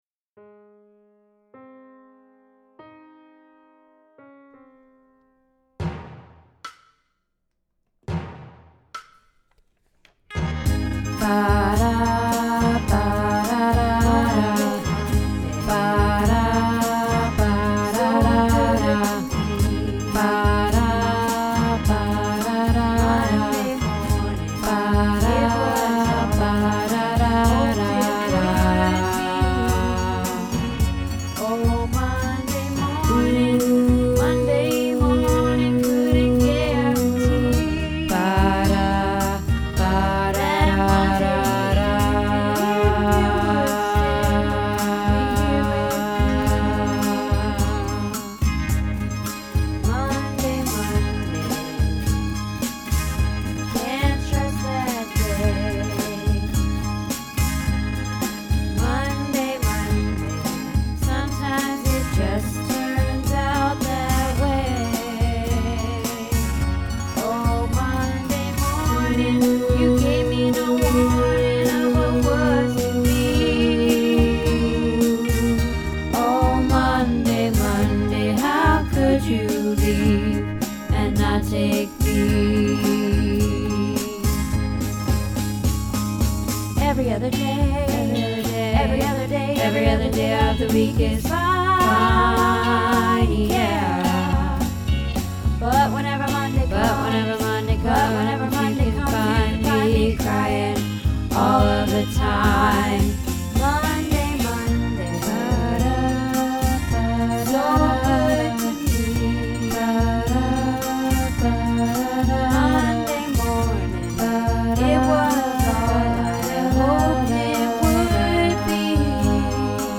Monday Monday - Bass